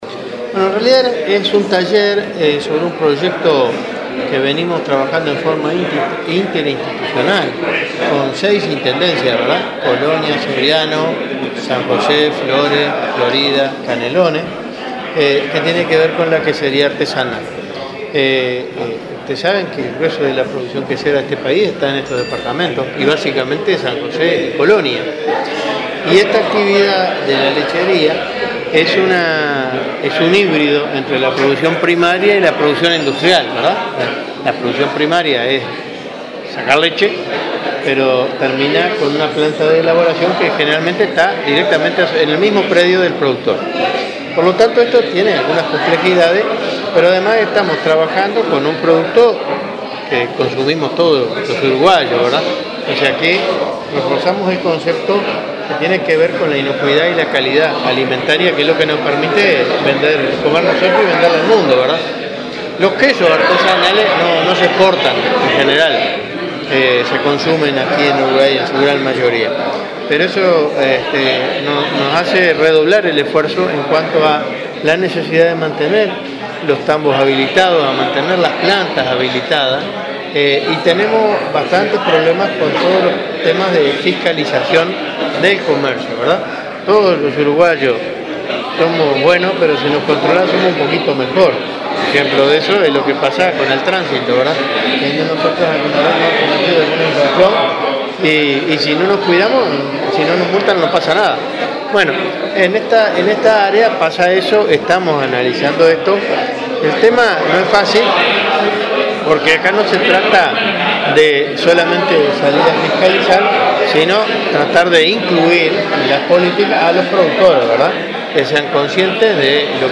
El ministro de Ganadería, Enzo Benech, exhortó a productores de queso artesanal a mantener habilitados sus tambos y plantas industriales para optimizar el comercio y recibir colaboración económica de programas oficiales. “Mi compromiso es ayudar a que estén en condiciones de ser habilitados, pero al que no esté formalizado no lo ayudamos más”, advirtió este viernes en un seminario sobre producción lechera en San José.